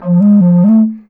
Index of /90_sSampleCDs/Best Service ProSamples vol.52 - World Instruments 2 [AIFF, EXS24, HALion, WAV] 1CD/PS-52 AIFF WORLD INSTR 2/WOODWIND AND BRASS/PS MOCENO BASSFLUTE LICKS